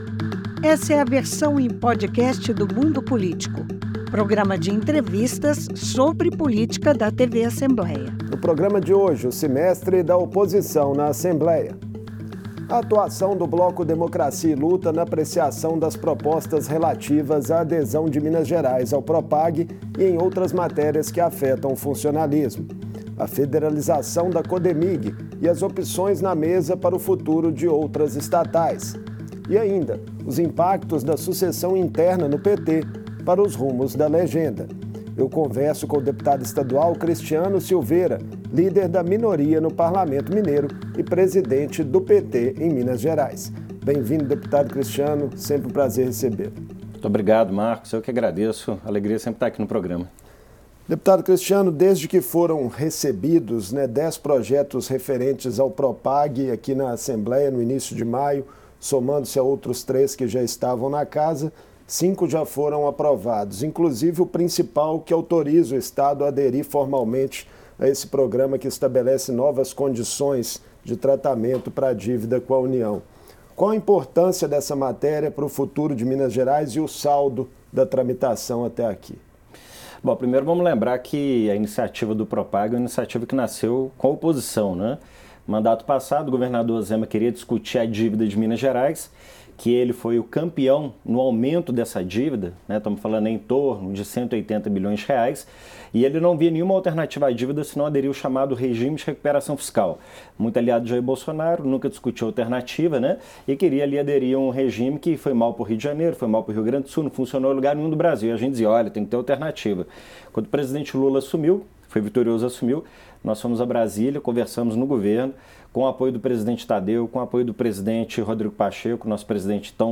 O primeiro período do ano legislativo foi marcado pela discussão dos projetos do Propag, o Programa de renegociação das dívida do Estados com a União. Em entrevista